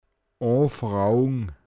pinzgauer mundart
o(n)fråogn anfragen